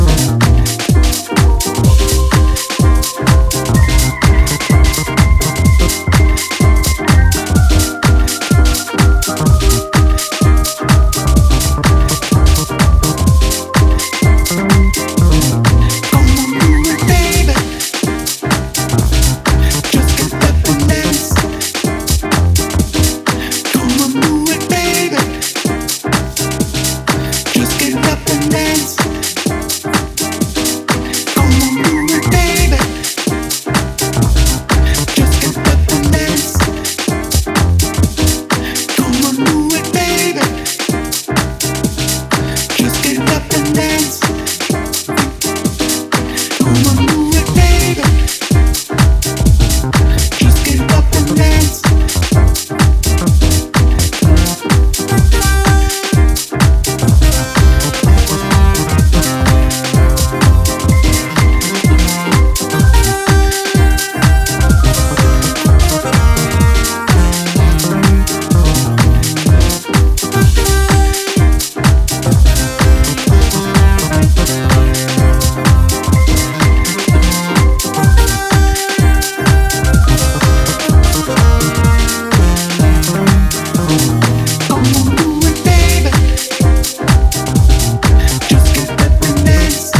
軽快なピアノリフとスペーシーなシンセワーク、ヴォーカルの絡みが小気味良い